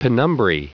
Prononciation du mot penumbrae en anglais (fichier audio)
penumbrae.wav